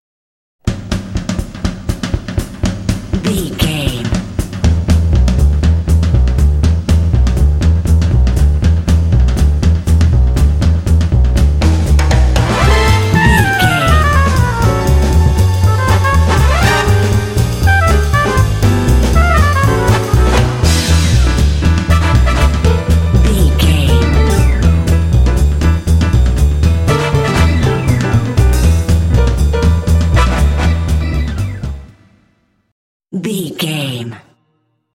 Uplifting
Aeolian/Minor
Fast
driving
energetic
lively
cheerful/happy
drums
double bass
piano
electric organ
brass
big band
jazz